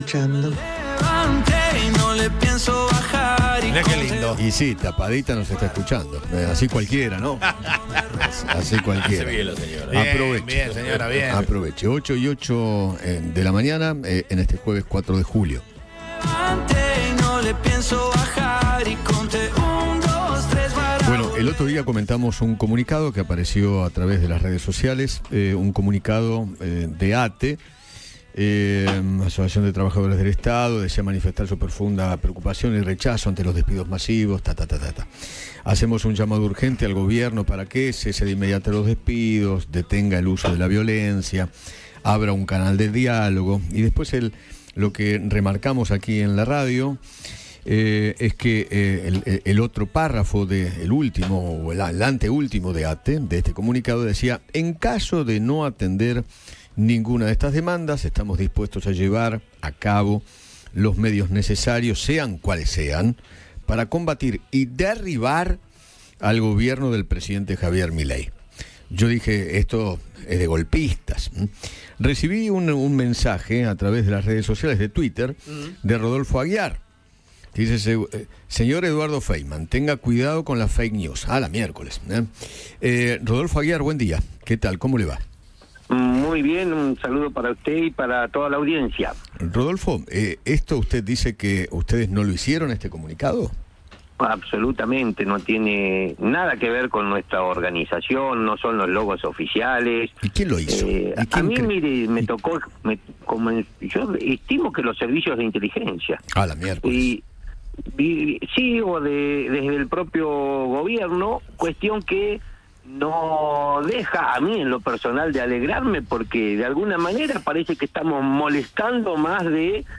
Eduardo Feinmann habló con Rodolfo Aguiar, secretario general de ATE, sobre las medidas de fuerza que está llevando acabo el gremio y afirmó que el comunicado en contra de Javier Milei es falso.